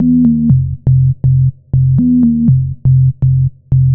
Classic Organ_121_E.wav